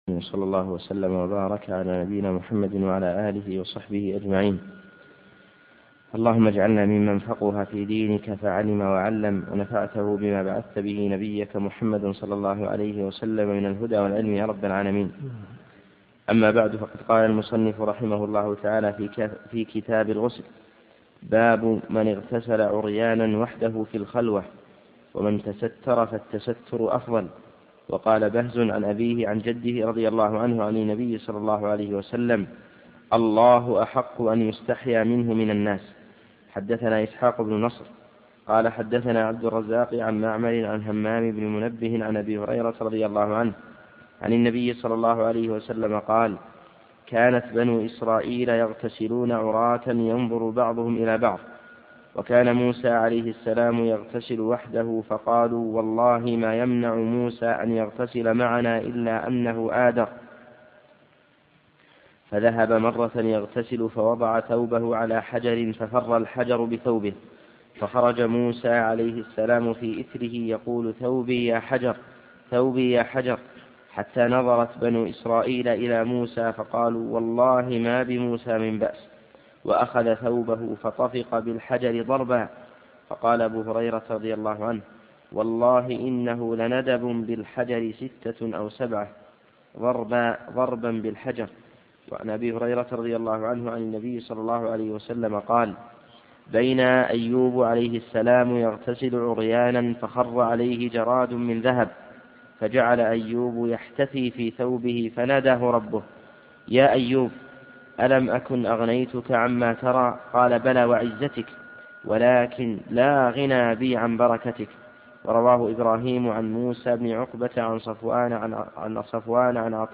دروس صوتيه ومرئية تقام في جامع الحمدان بالرياض
كتاب الغسل - من حديث 278 -إلى- حديث 283 - الصوت متقطع في أماكن مختلفة.